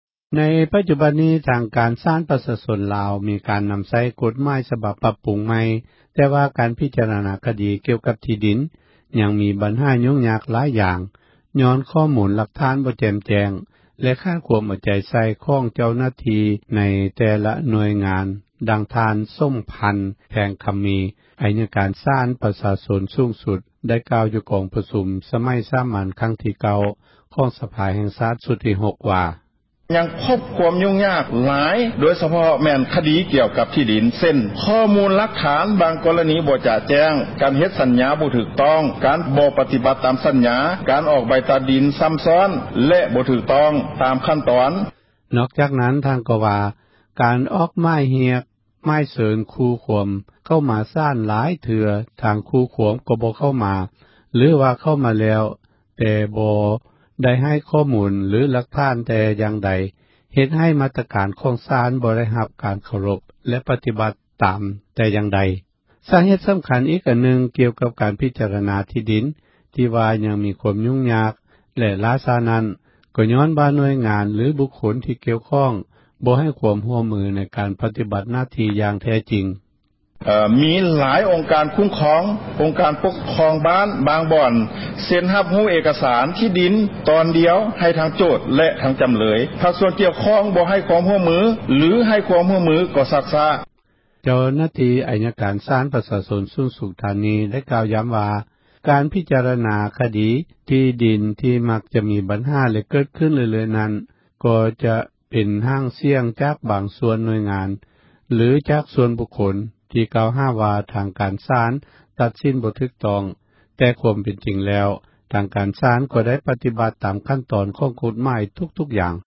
ໃນປັຈຈຸບັນນີ້ ທາງກາຣສາລ ປະຊາຊົນລາວ ມີກາຣນຳໃຊ້ ກົດໝາຍສະບັບ ປັບປຸງໃໝ່ ແຕ່ວ່າ ກາຣພິຈາຣະນາ ຄະດີ ກ່ຽວກັບທີ່ດິນ ຍັງມີບັນຫາ ຫຍຸ້ງຍາກຍ້ອນ ຂໍ້ມູລຫລັກຖານ ບໍ່ແຈ່ມແຈ້ງ ແລະຂາດຄວາມເອົາ ໃຈໃສ່ ຂອງທາງເຈົ້າໜ້າທີ່ ໃນແຕ່ລະໜ່ວຍງານ ດັ່ງທ່ານສົມພັນ ແພງຄຳມີ ອັຍກາຣສາລ ປະຊາຊົນ ສູງສຸດ ໄດ້ກ່າວ ຢູ່ກອງປະຊຸມ ສມັຍສາມັນ ຄັ້ງທີ່ 9 ຂອງສະພາແຫ່ງຊາຕ ຊຸດທີ່ 6 ວ່າ: